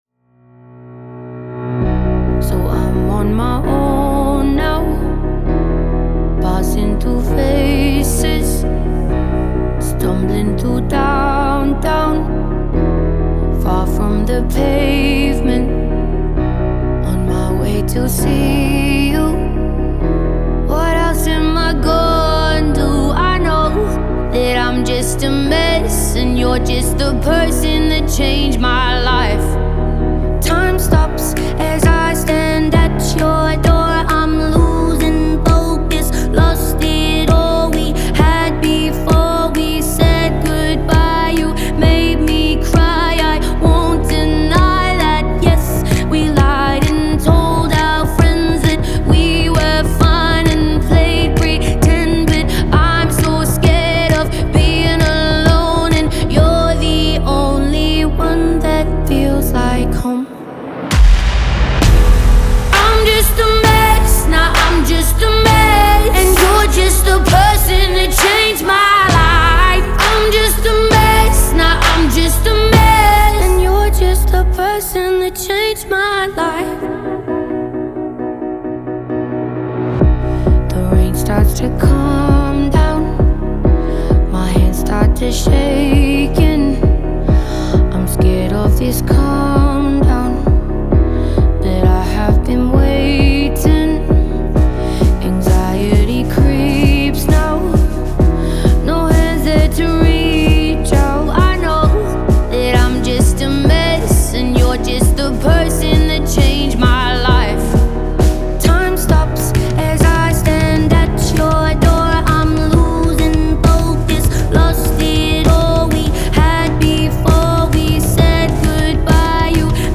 выполненный в жанре поп с элементами инди.